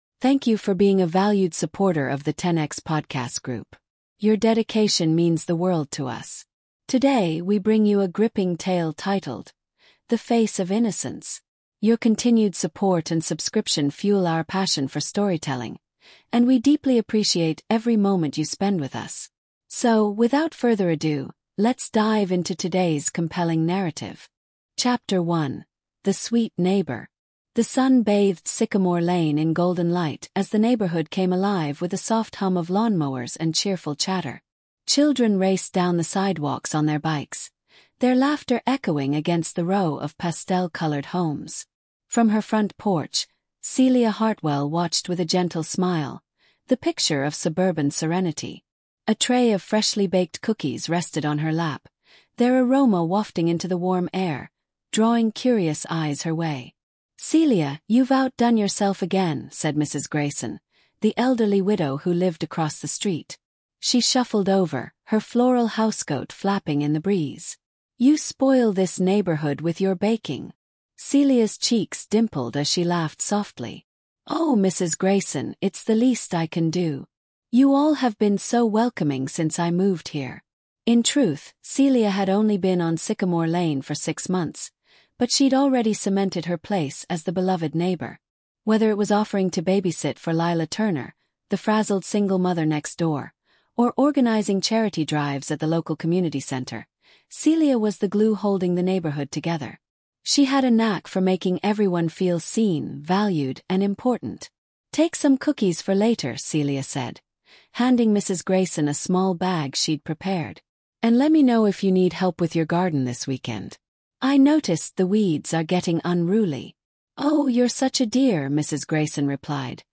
The Face of Innocence is a thrilling storytelling podcast that delves into the chilling tale of a seemingly sweet neighbor who manipulates her community into trusting her, only to frame them for her own crimes. As her mask of innocence begins to slip, two determined individuals band together to expose her dark secrets.